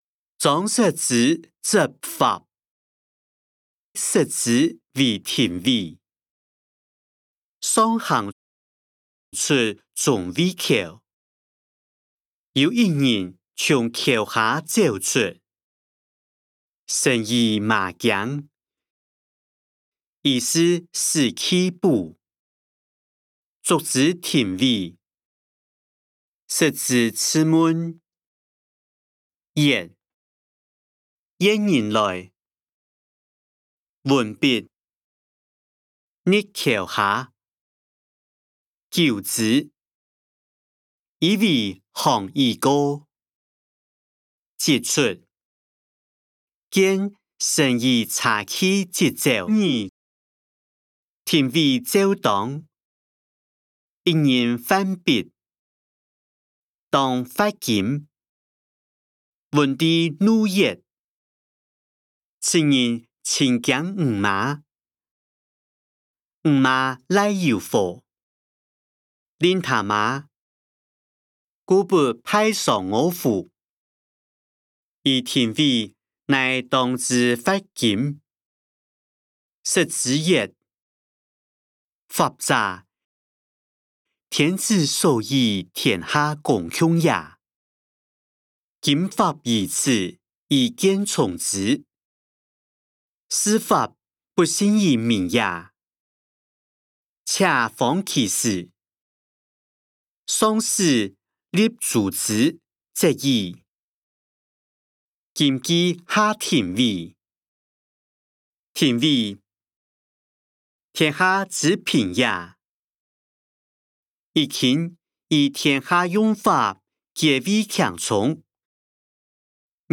歷代散文-張釋之執法音檔(四縣腔)